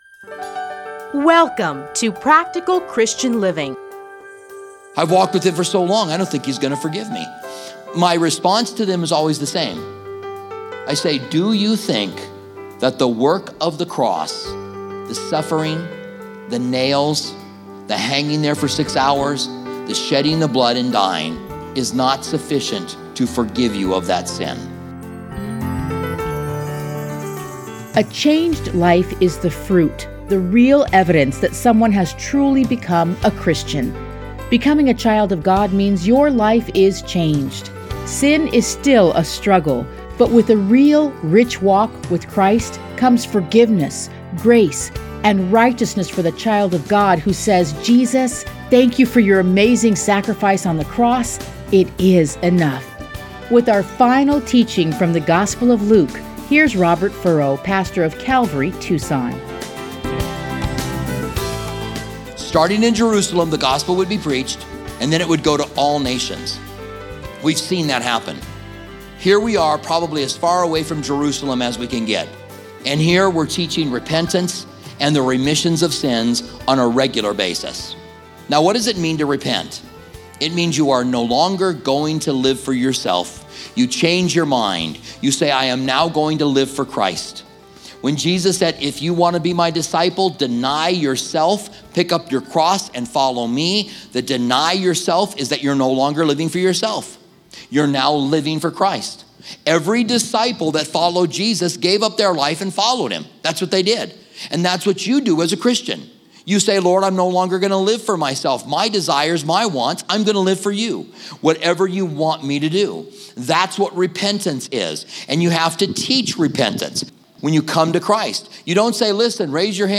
Listen to a teaching from Luke 24:44-53.